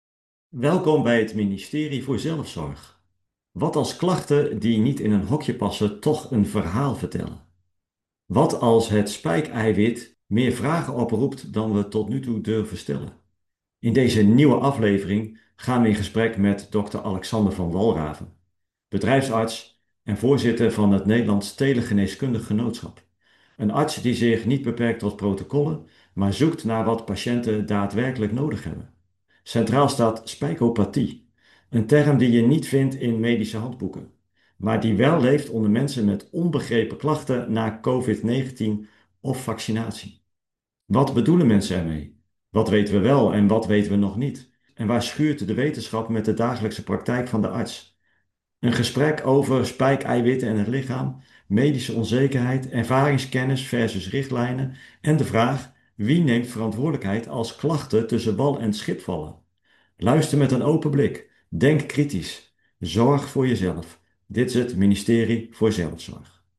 Een gesprek over: